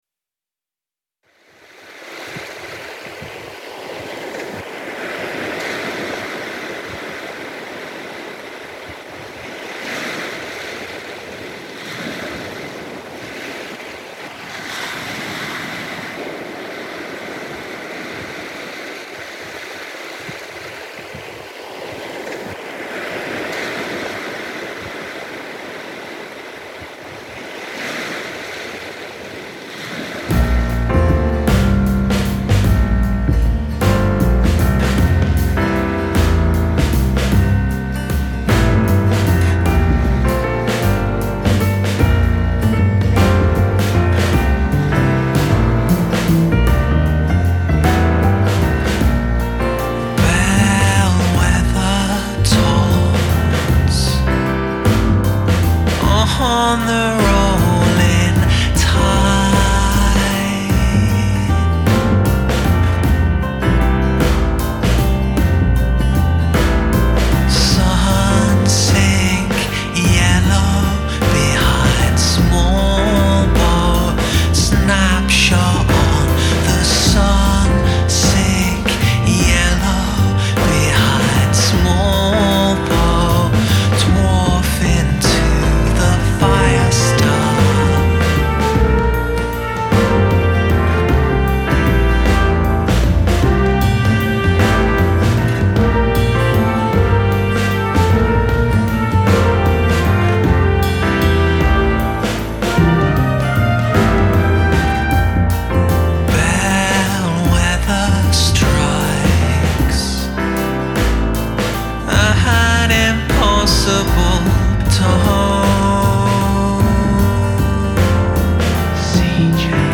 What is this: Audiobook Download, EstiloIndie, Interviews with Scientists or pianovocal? EstiloIndie